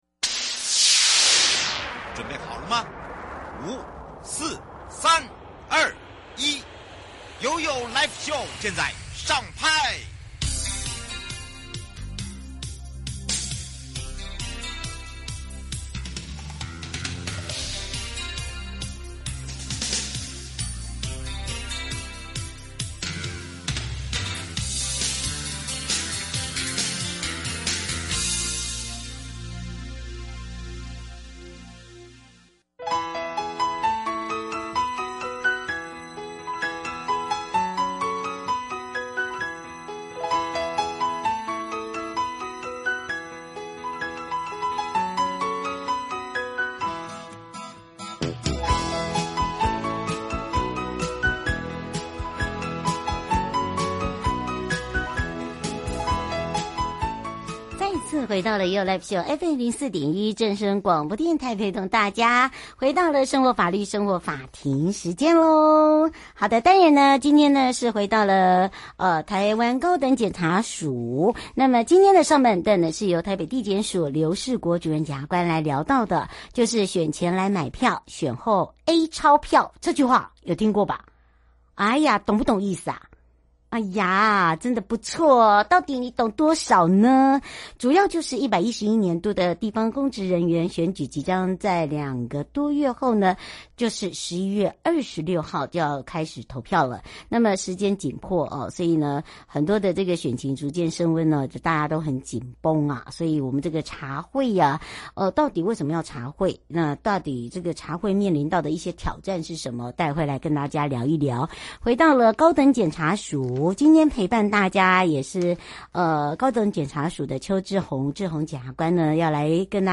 受訪者： 臺北地檢署劉仕國主任檢察官 節目內容： 1. 選前來買票，選後A鈔票~這句話您懂嗎 ？